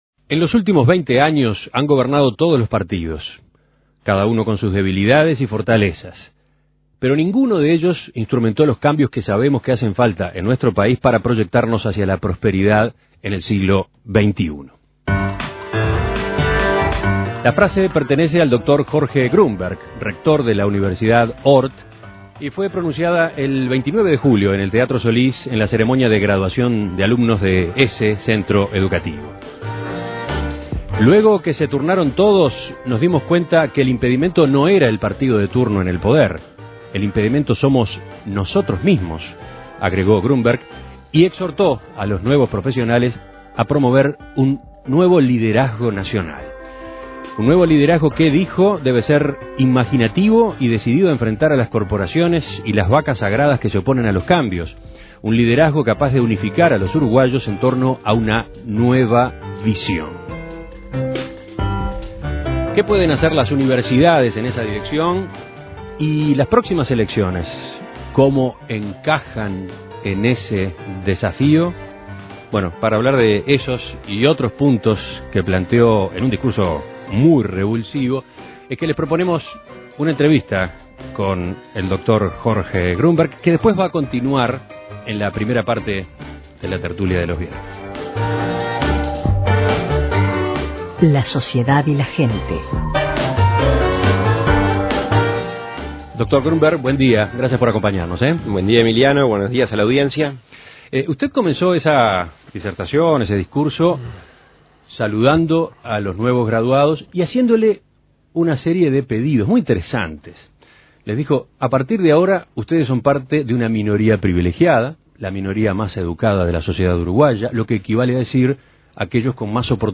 Entrevista en Radio El Espectador